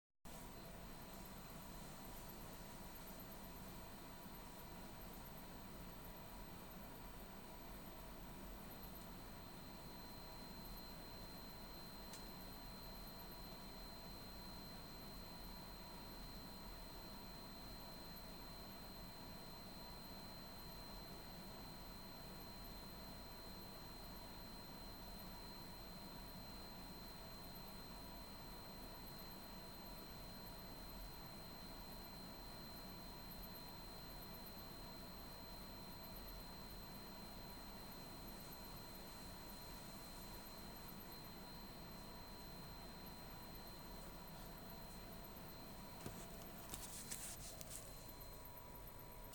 Merci pour vos remarques en espérant que de votre coté tout fonctionne maintenant un bruit "strident" ou type ultrason est émis de l'unité extérieure, puis est transmis via la gaine et arrive via l'unité intérieure du gainable dans la buanderie et c'est insupportable.
doublon 45330 unite interieure.mp3
Votre sifflement est vers les 4 kHz, il est dû au hachage d'une tension, soit au niveau de l'IPM (qui envoie non pas des sinusoïdes triphasées sur le compresseur, mais des signaux très complexes à base d'allers/retours entre le 0V et la haute tension, 340V continus), soit au niveau du PFC qui hache la tension secteur pour qu'elle soit plus stable sur les gros condensateurs 400V... A chaque fois des bobinages vibrent, ceux du compresseur ou l'inductance PFC.